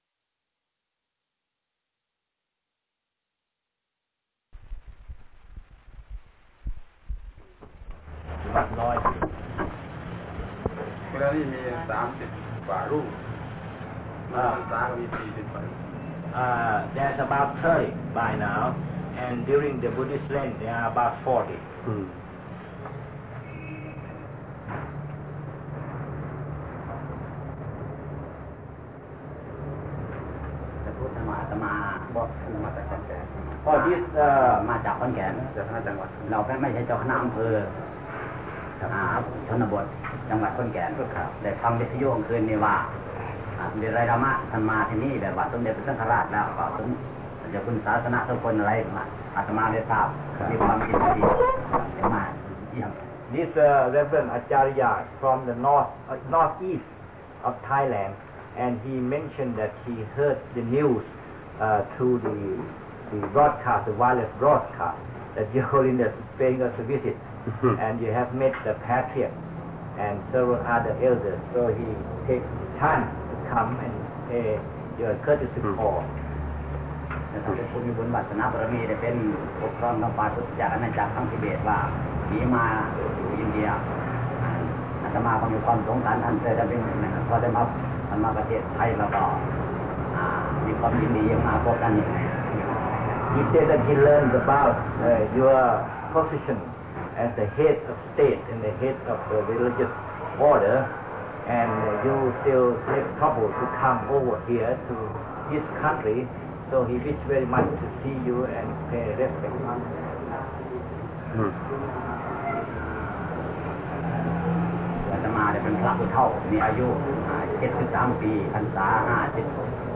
พระธรรมโกศาจารย์ (พุทธทาสภิกขุ) - สนทนาธรรมกับ ดาไลลามะ ให้โอวาทแก่ พระภิกษุ สามเณร ญาติโยม และอื่นๆ